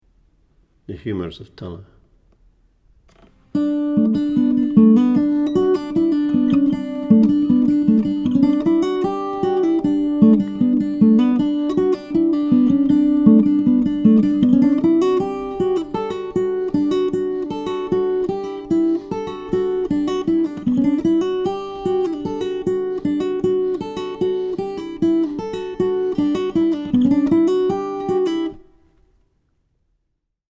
DIGITAL SHEET MUSIC - FINGERPICKING GUITAR SOLO
Celtic session tune, DADGAD tuning